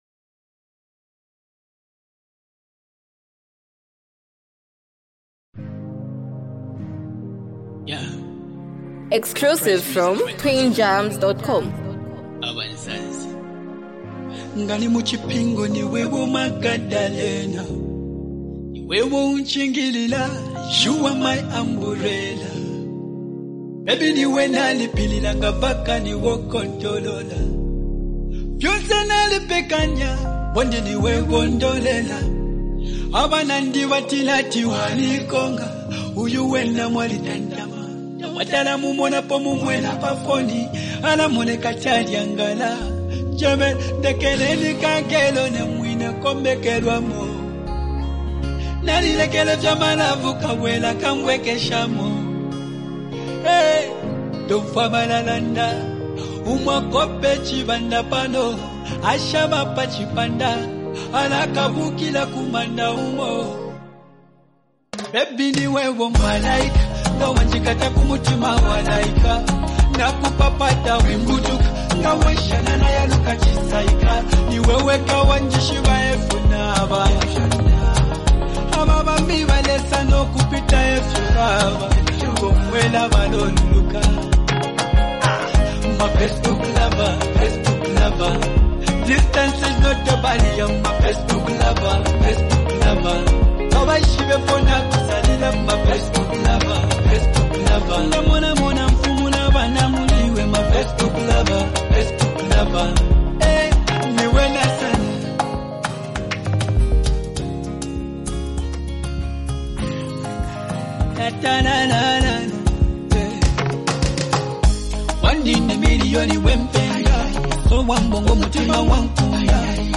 hype, love vibes, and a powerful hook
unique singing flow